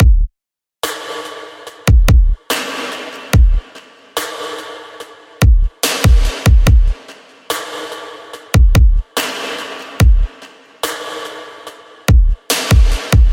暧昧的爱的鼓声
描述：混响被点亮
Tag: 72 bpm Ambient Loops Drum Loops 2.24 MB wav Key : Unknown